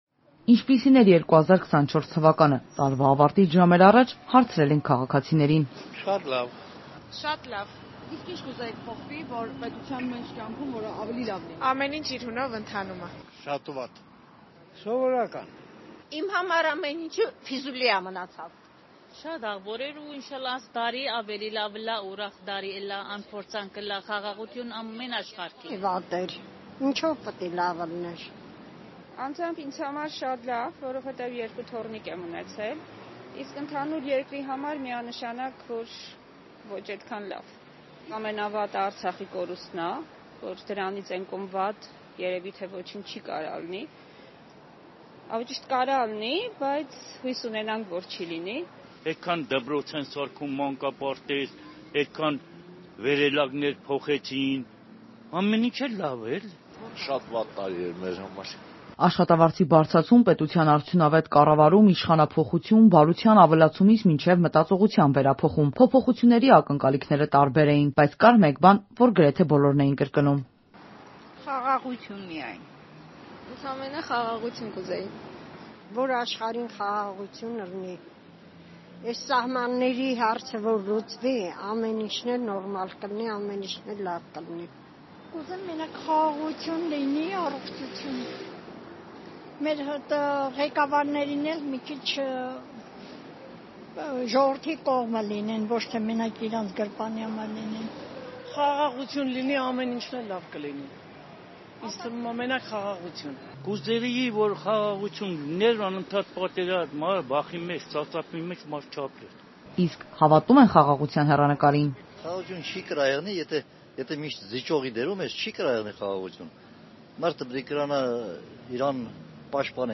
Ինչպիսի՞ն էր 2024-ը. հարցում Երևանում